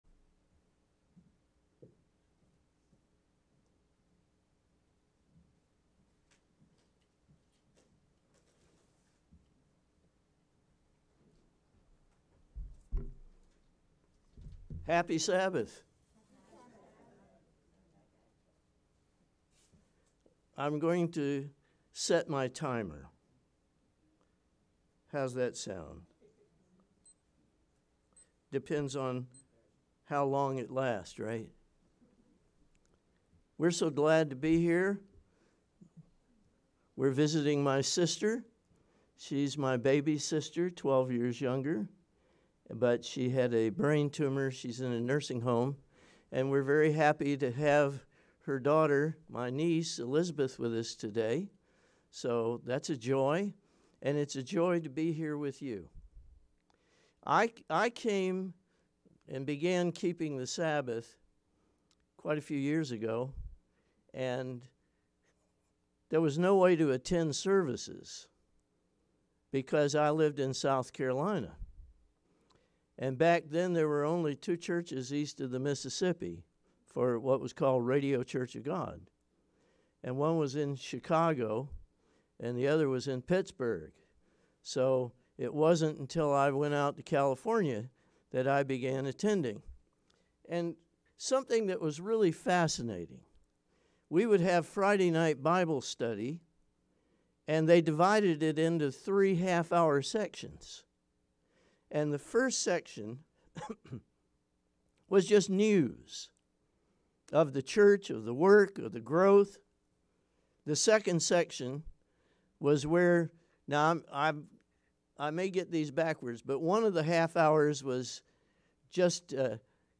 Sermons
Given in St. Petersburg, FL